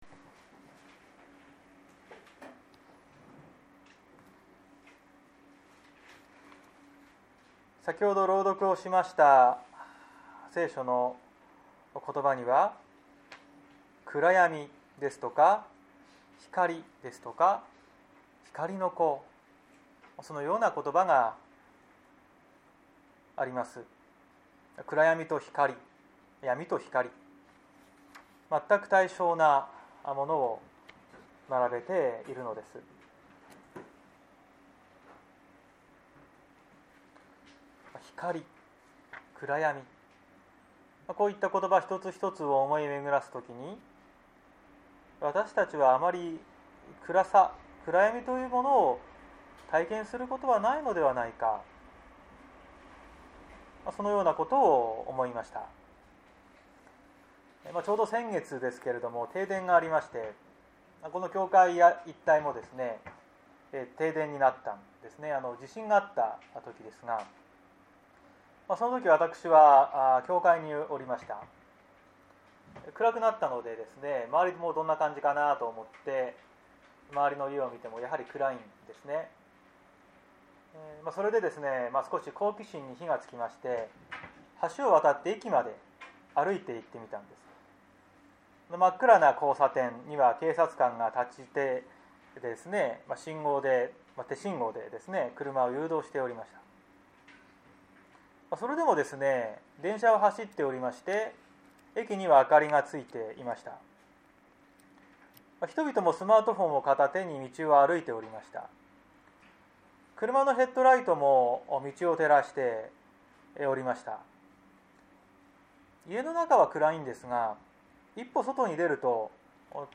2021年03月14日朝の礼拝「光の子として生きる」綱島教会
説教アーカイブ。